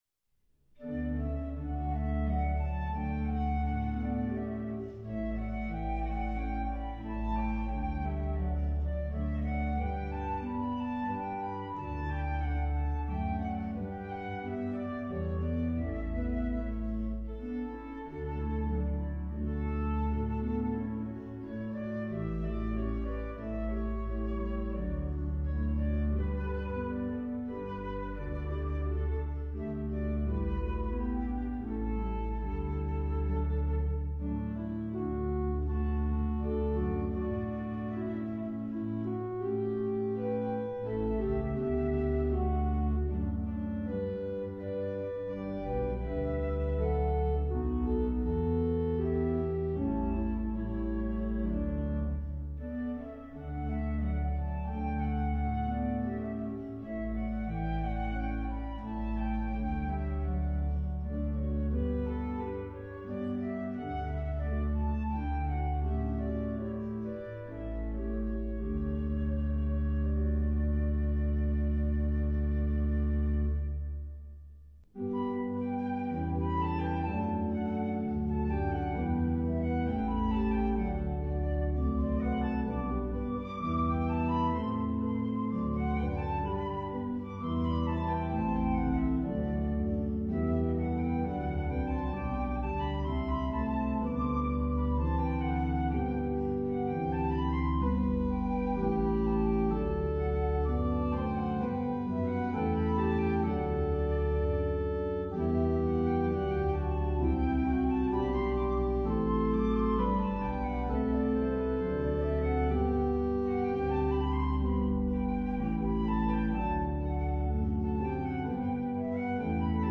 Voicing: Instrument and Organ